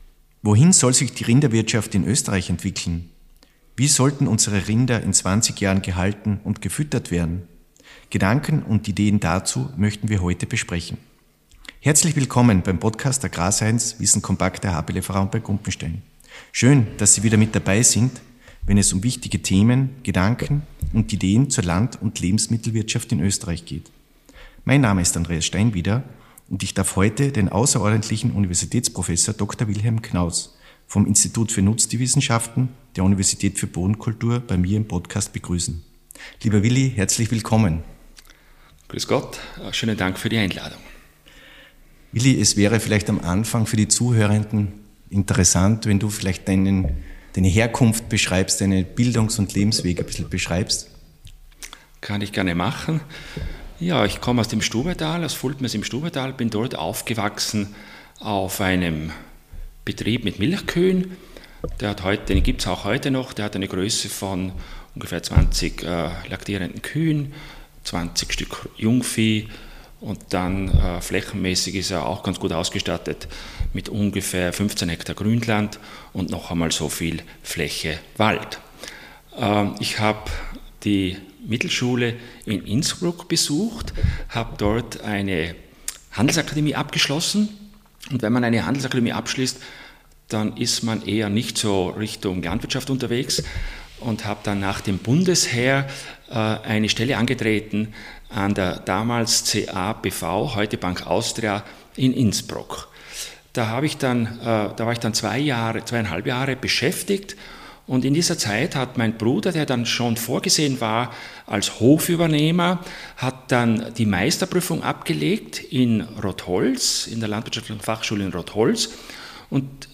Podcast-Gespräch